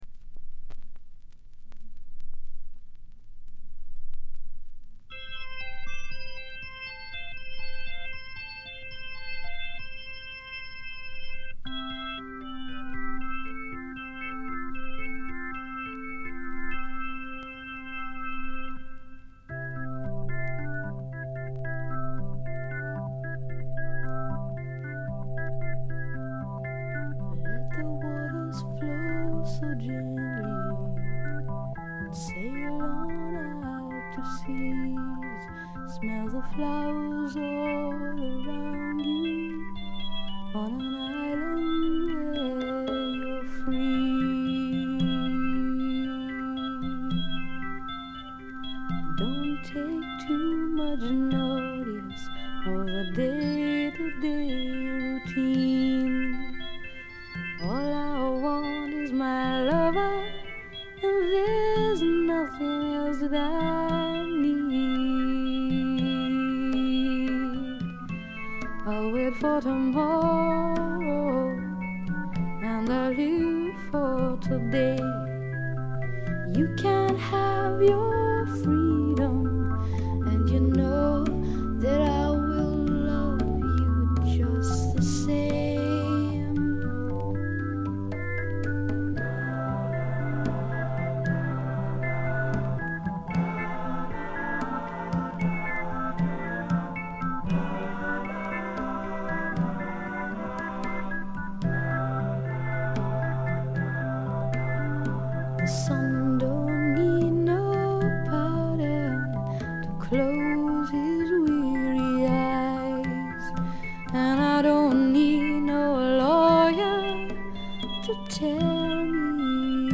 静音部でバックグラウンドノイズ、チリプチ少々、散発的なプツ音少し。特に目立つノイズはありません。
ほとんど弾き語りのような曲が多いのもよいですね。
試聴曲は現品からの取り込み音源です。